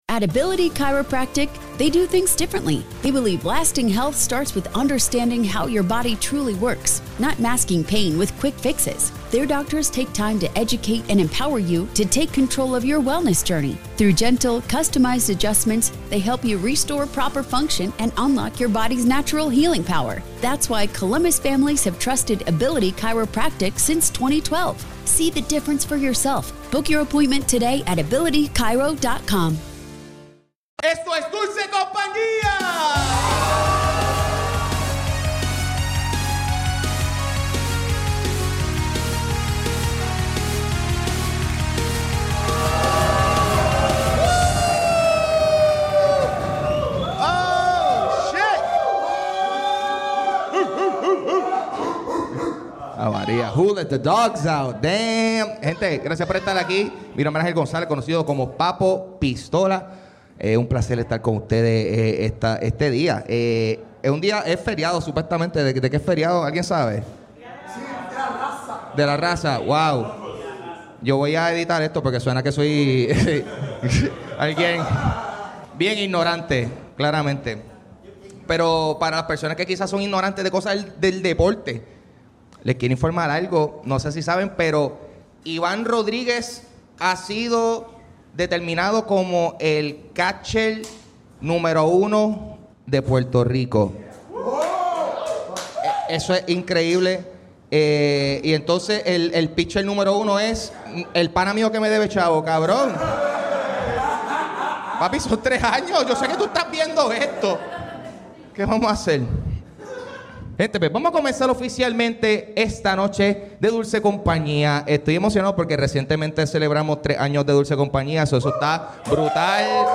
Open Mic | Octubre 13, 2025
Participó una cantidad razonable de comediantes.